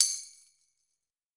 Cardi Tam Hit 3.wav